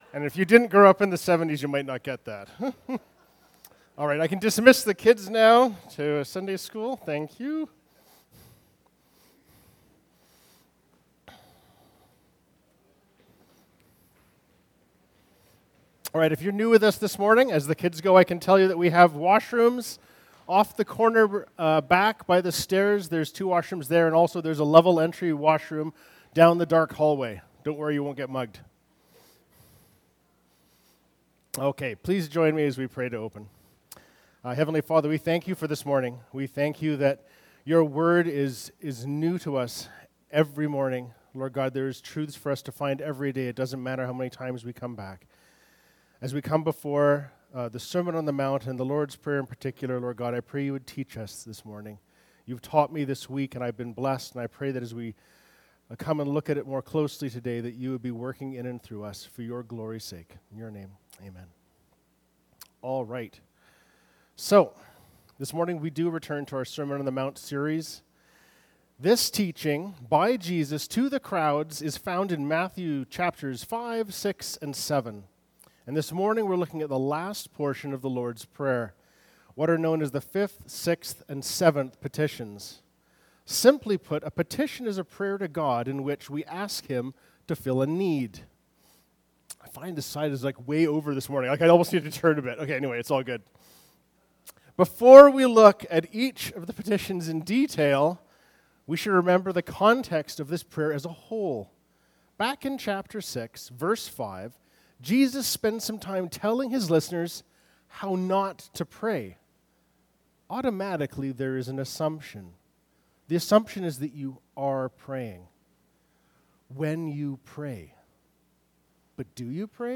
Feb 02, 2020 Per Diem (Matthew 6:5-15) MP3 SUBSCRIBE on iTunes(Podcast) Notes Discussion Sermons in this Series Loading Discusson...